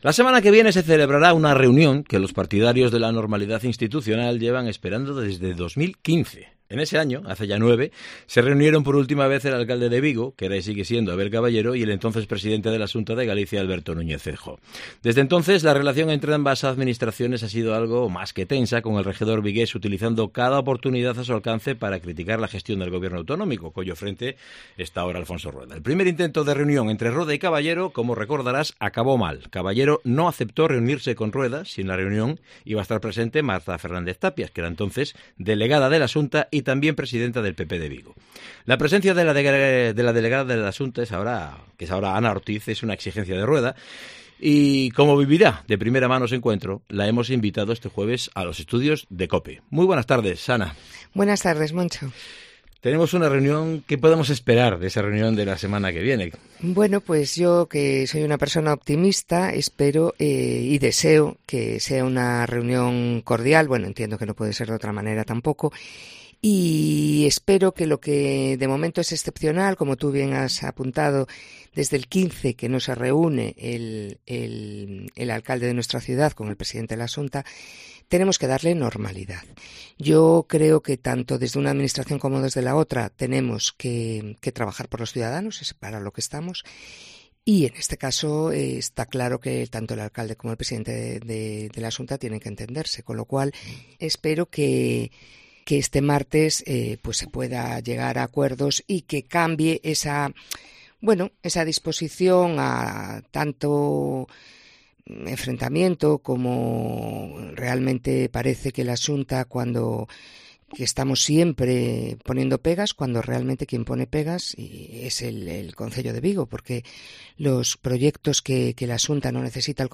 Entrevista con Ana Ortiz, delegada de la Xunta en Vigo